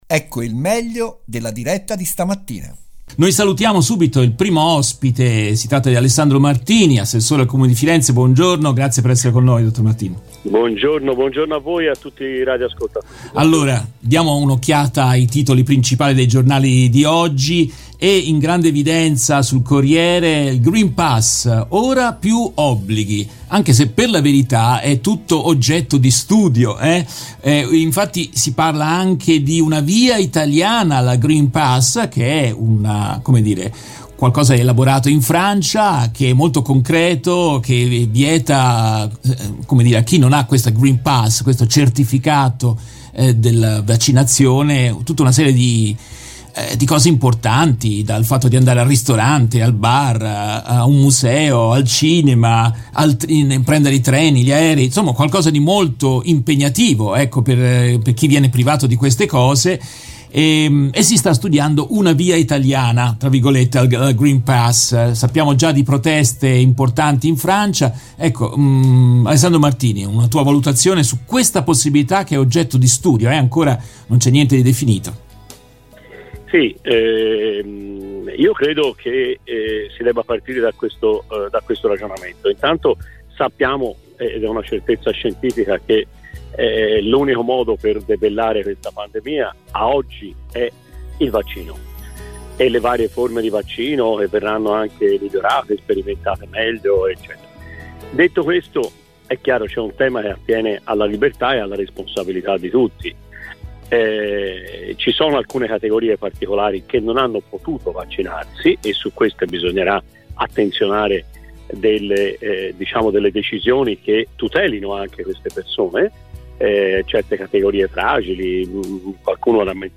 In questa intervista tratta dalla diretta RVS del 15 luglio 2021, ascoltiamo l'assessore al Comune di Firenze Alessandro Martini.